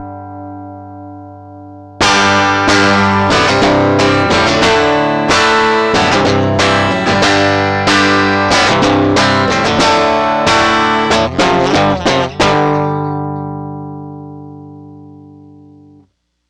Simulated preamp distortion with a guitar signal... (output in